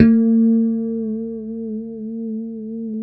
B3 PICKHRM2B.wav